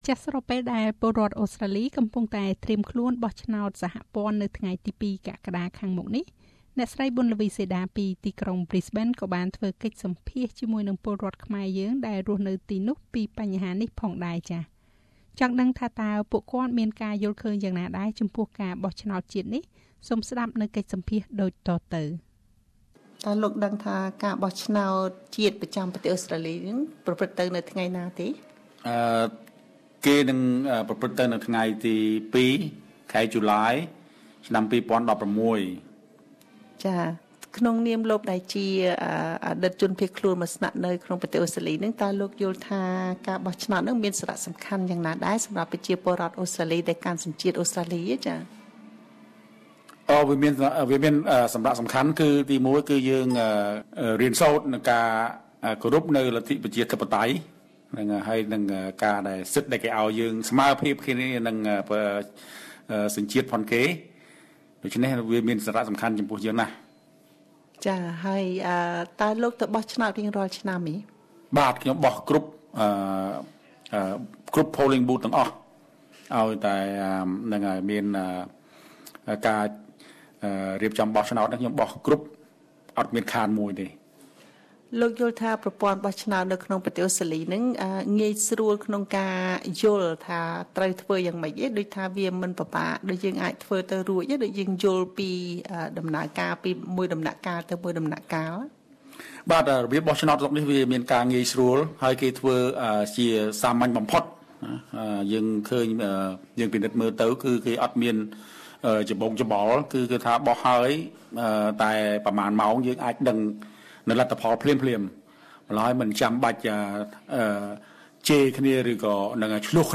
ពលរដ្ឋខ្មែរនៅប្រ៊ីសបេន និយាយពីការបោះឆ្នោតជាតិ